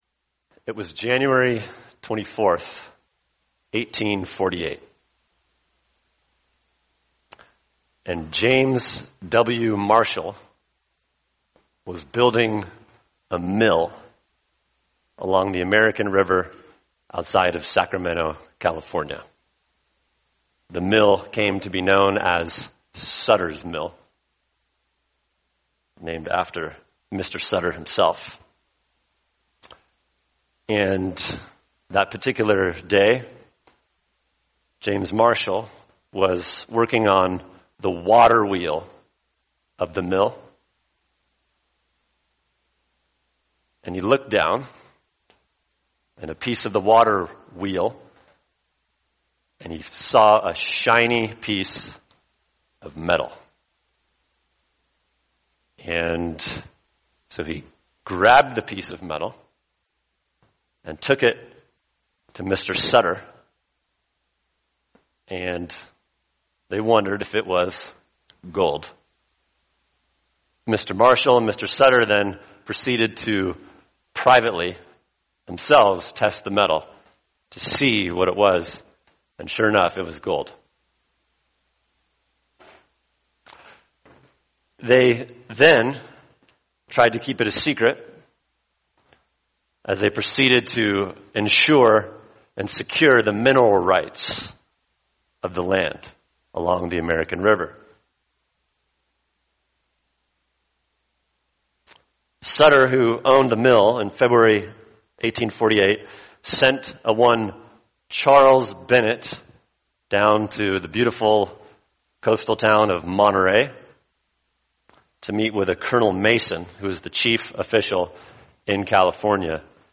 [sermon] 1 John 5:1-5 The Victorious Faith | Cornerstone Church - Jackson Hole